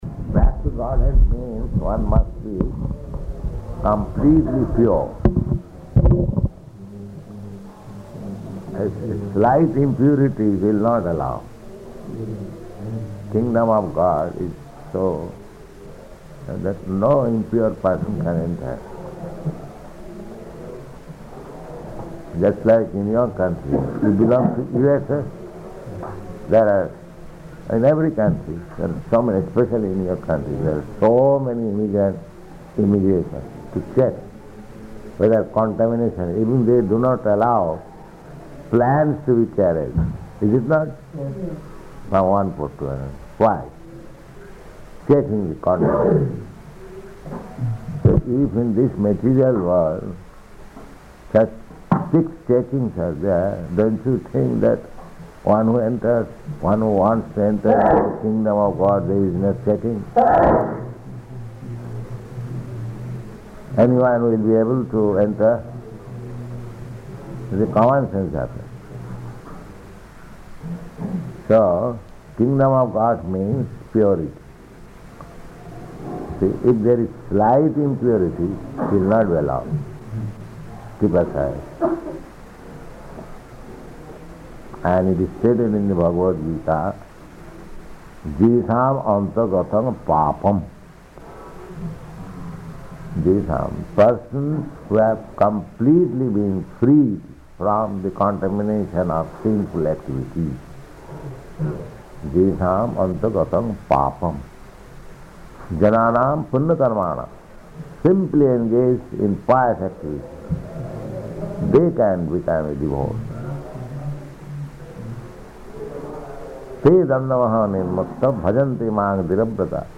Śrīmad-Bhāgavatam 6.1.38 --:-- --:-- Type: Srimad-Bhagavatam Dated: December 20th 1970 Location: Surat Audio file: 701220SB-SURAT.mp3 Prabhupāda: Back to Godhead means one must be completely pure.